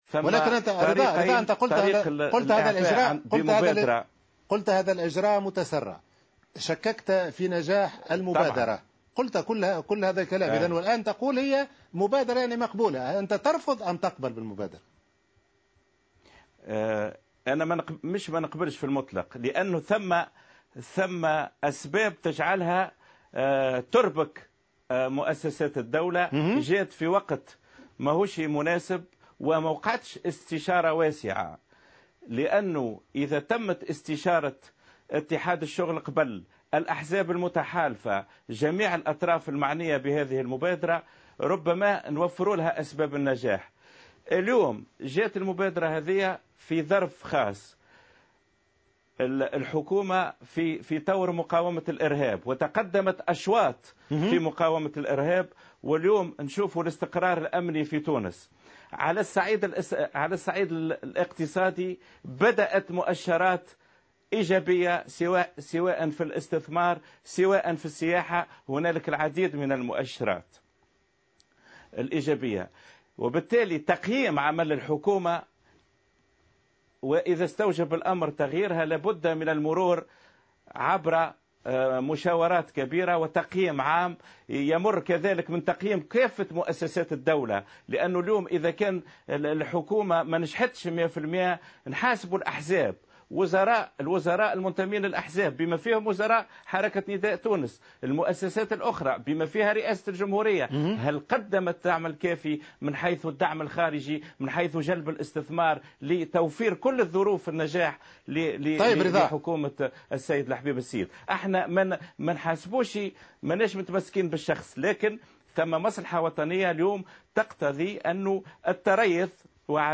واعتبر هذه المبادرة في حوار مباشر مساء أمس على قناة "فرانس 24"، محاولة للضغط على رئيس الحكومة الحالي لحمله على الاستقالة لأنه لا يمكن إقالته دستوريا.